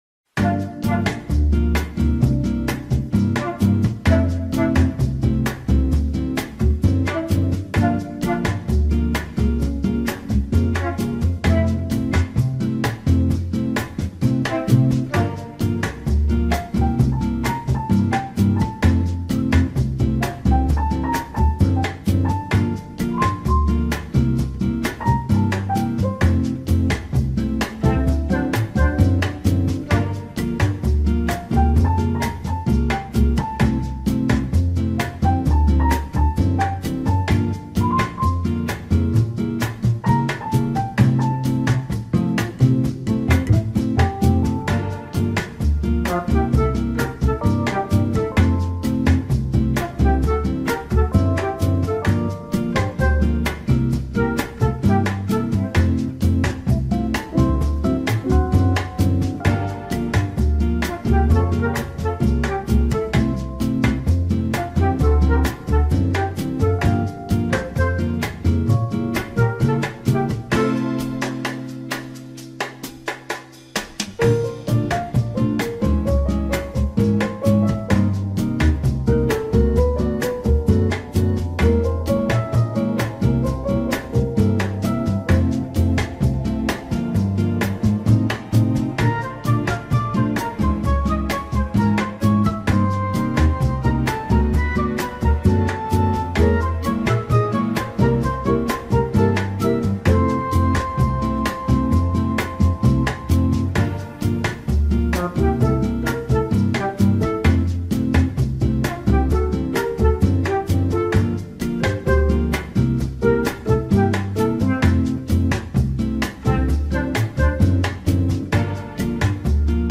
La-mejor-música-de-elevador-o-ascensor.mp3